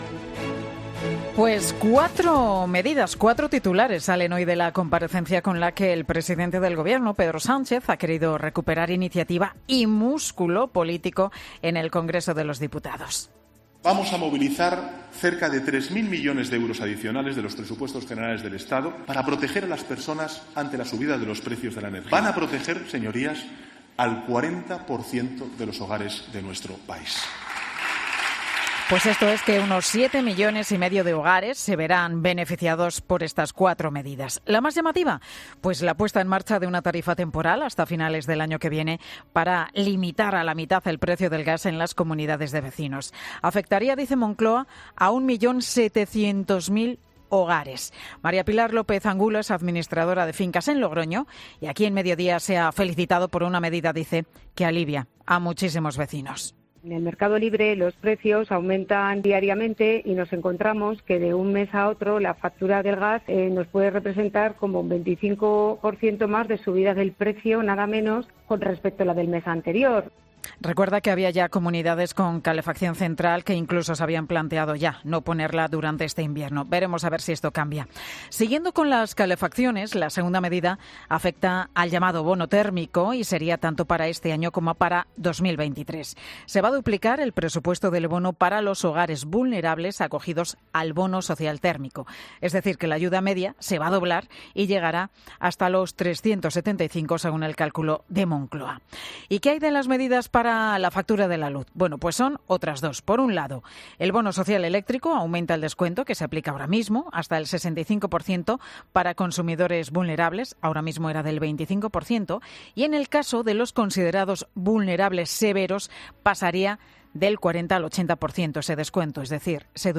Pedro Sánchez comparece en el Congreso de los Diputados a petición propia para explicar su gestión ante la crisis energética.